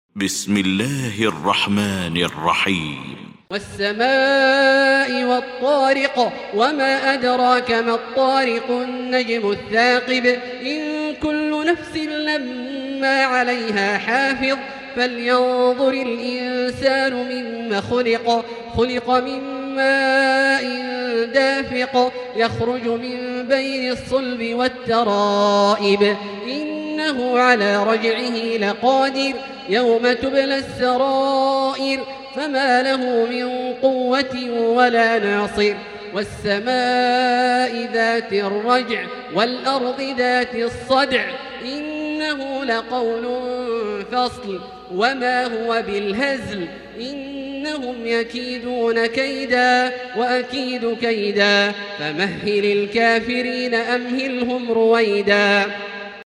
المكان: المسجد الحرام الشيخ: فضيلة الشيخ عبدالله الجهني فضيلة الشيخ عبدالله الجهني الطارق